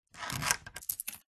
Звук дверной цепочки на входной двери 2